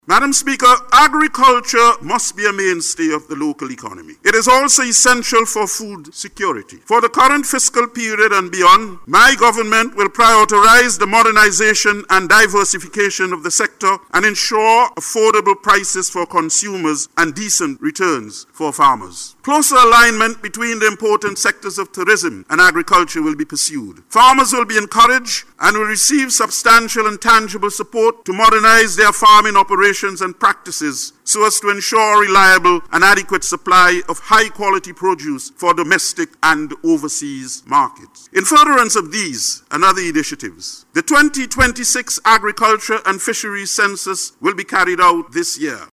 He made this statement while delivering the Throne Speech, which outlined the Government’s vision for the future of St. Vincent and the Grenadines.